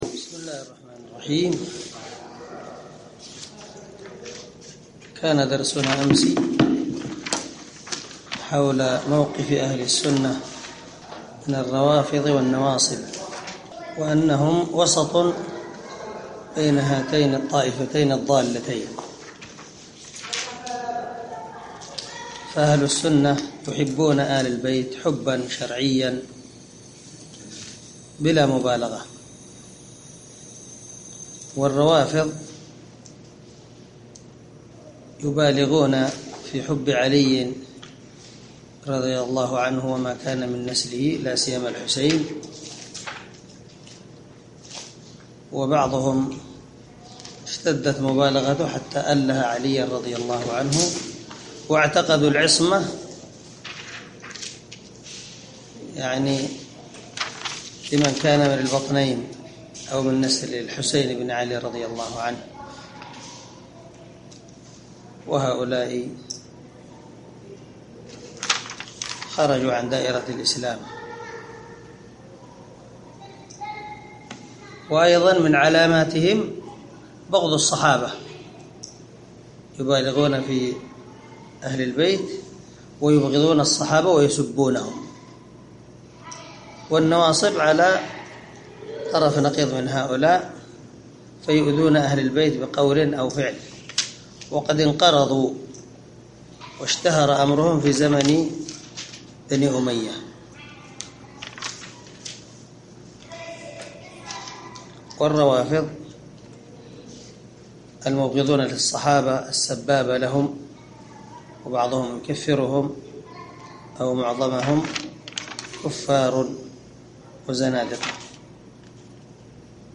عنوان الدرس: الدرس الواحد والثمانون